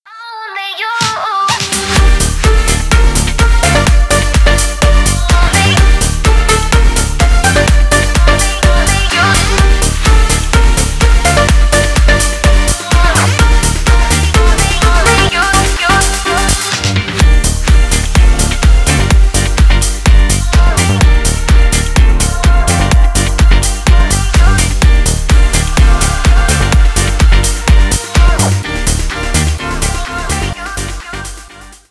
• Качество: 320 kbps, Stereo
Танцевальные